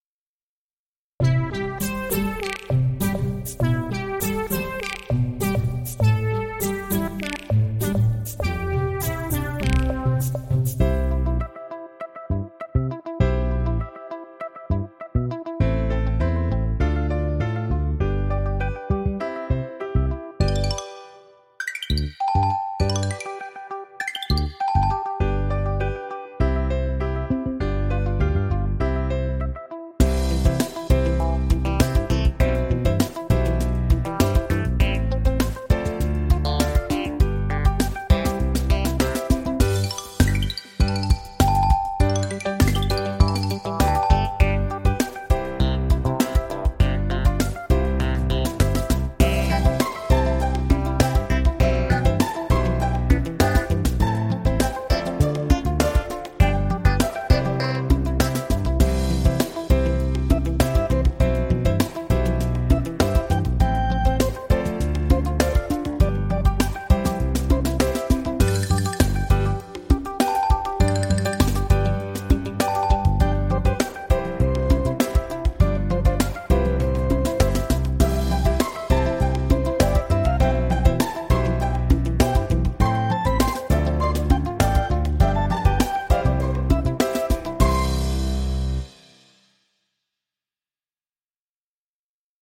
C调伴奏